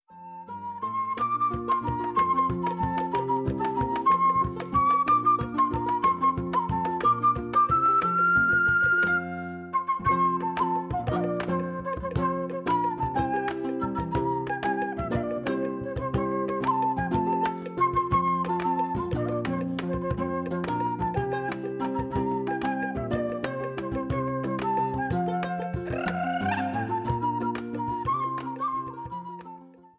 Weihnachtstrio